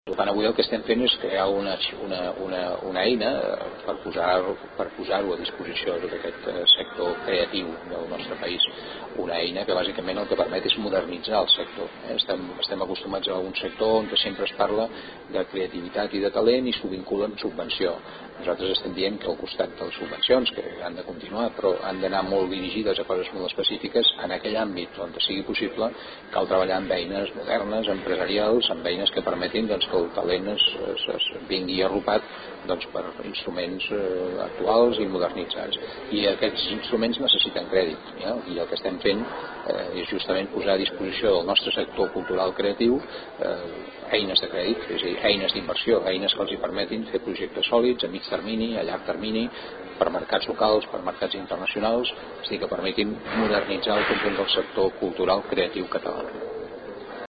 Tall de veu del conseller de Cultura, Ferran Mascarell